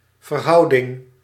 Ääntäminen
US : IPA : [ˈɹeɪ.ʃi.ˌoʊ]